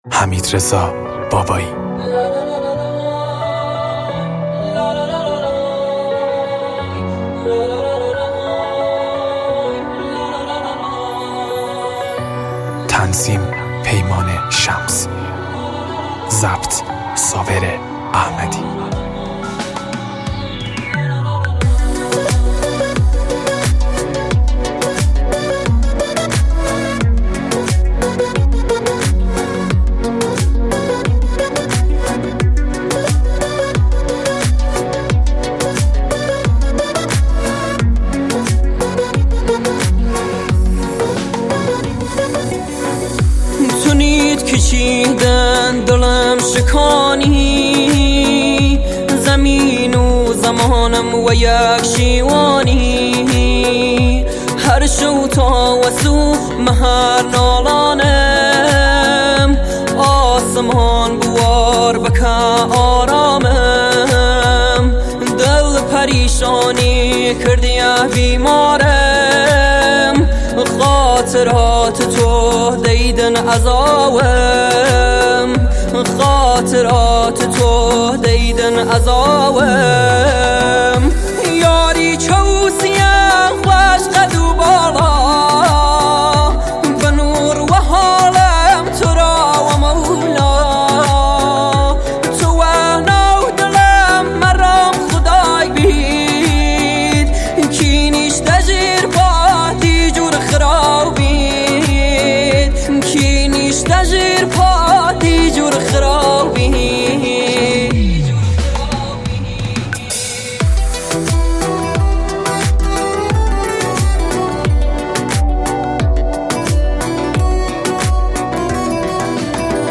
کردی